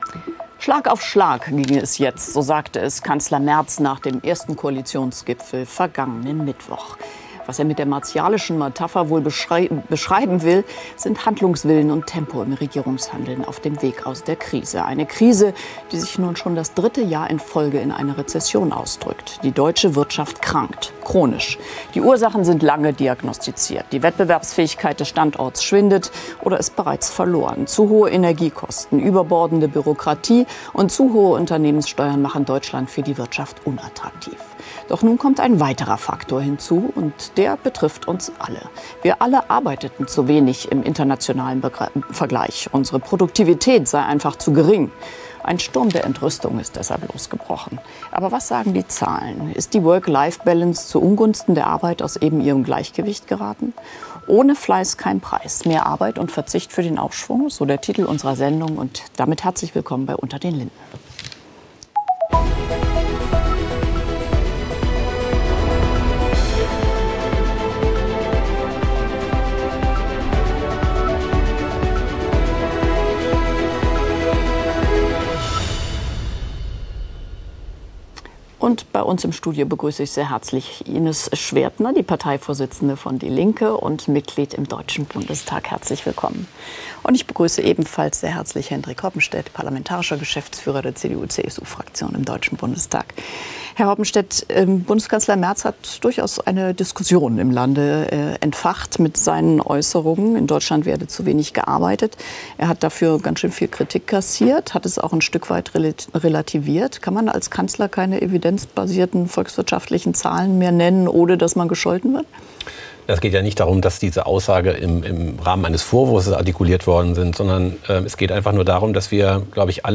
„unter den linden“ ist das politische Streitgespräch bei phoenix.